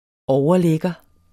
Udtale [ ˈɒwʌˈ- ]